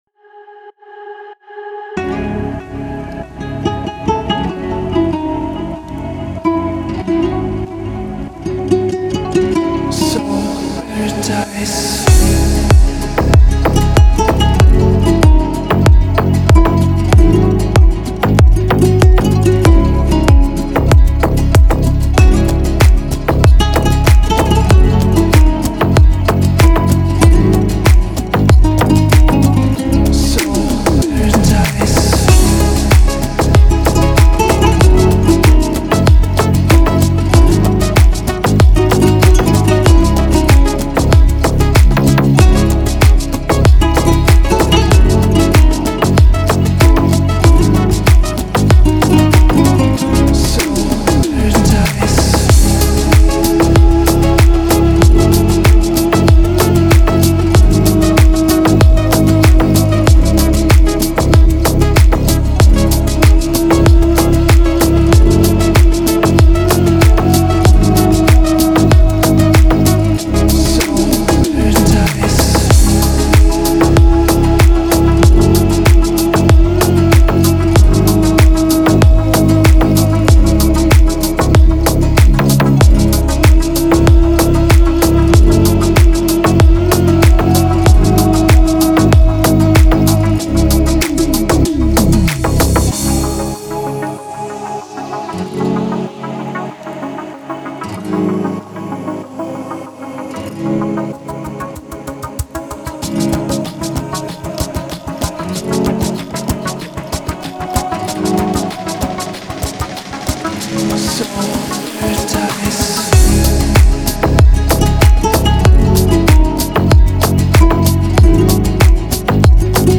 красивая музыка